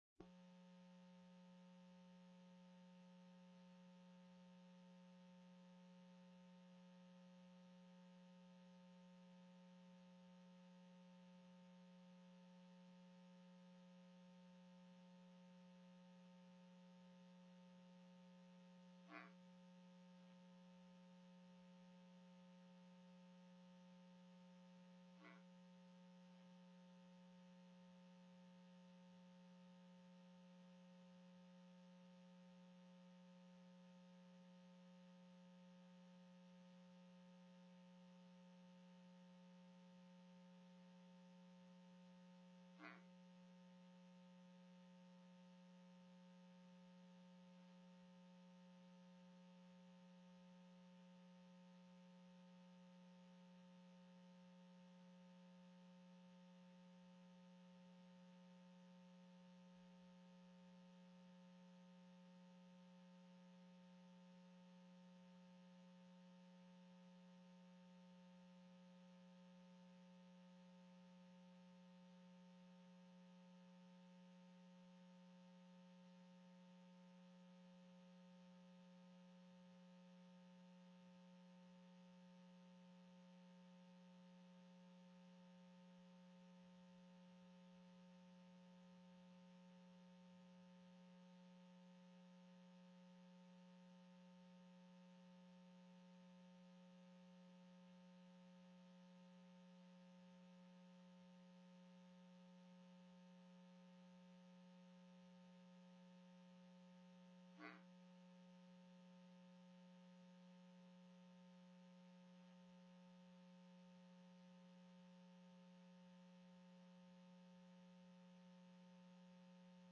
02/16/2023 01:30 PM House FINANCE
+ teleconferenced
Co-Chair Johnson reviewed the meeting agenda.